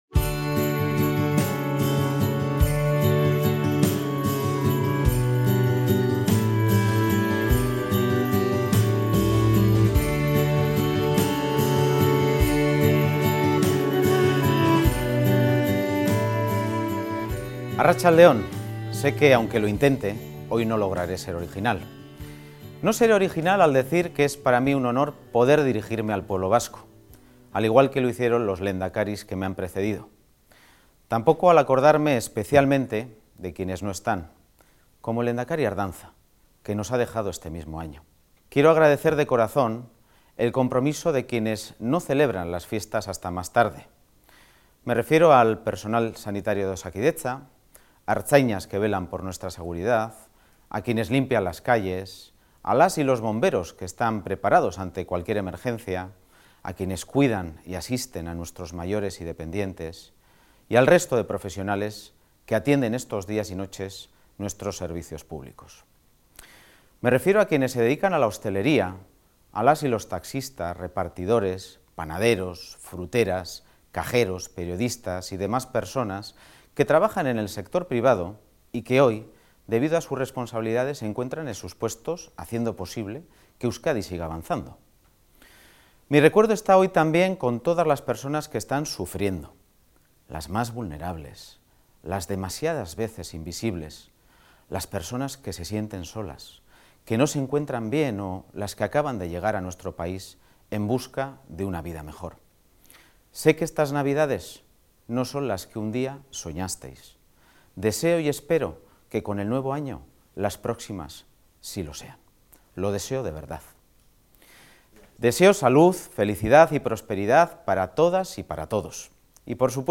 Discurso de fin de año
El Lehendakari, Imanol Pradales, en su mensaje de fin de año / Europa Press